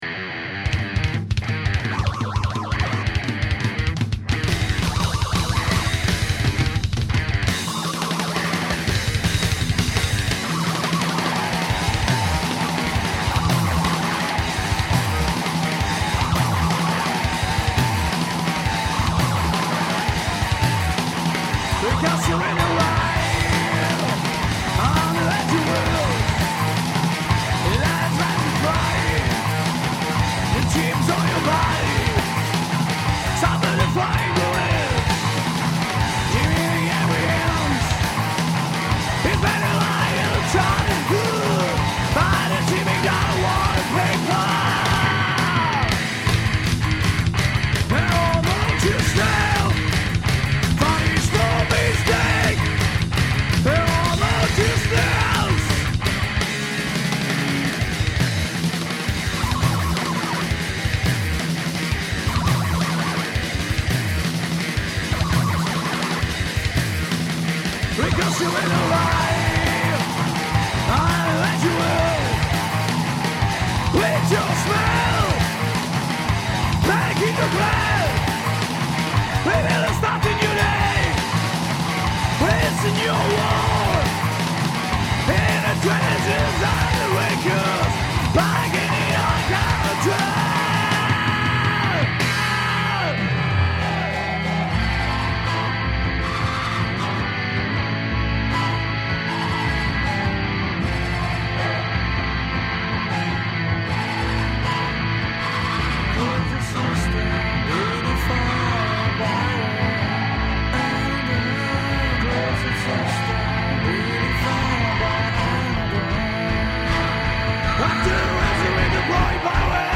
vocal, guitar
drums
bass